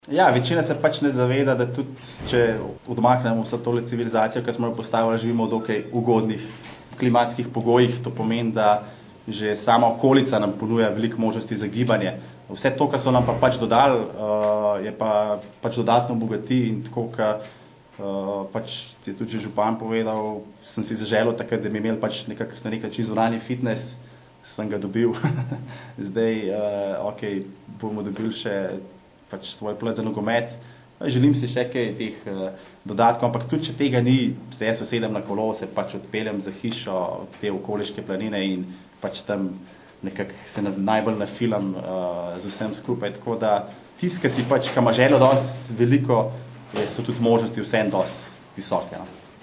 izjava_zankosir2.mp3 (1,2MB)